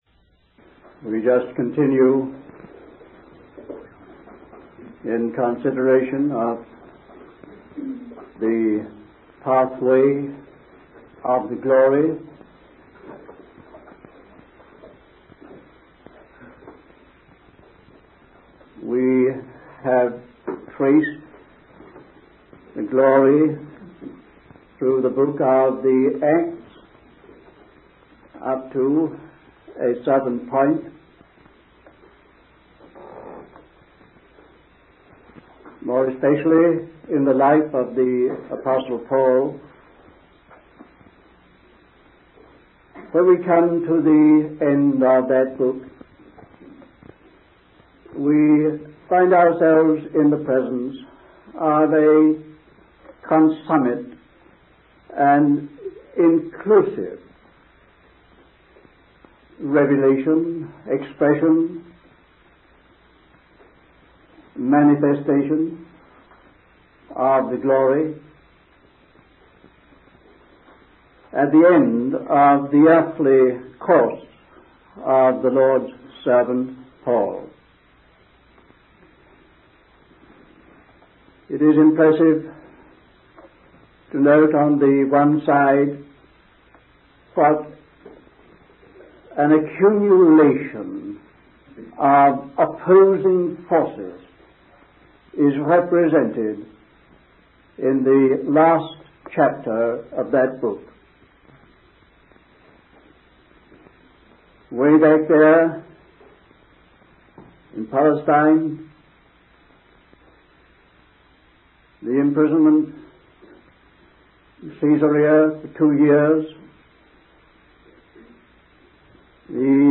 In this sermon, the speaker discusses the last chapter of the book of Acts, which represents an accumulation of opposing forces against the preaching of the word of God.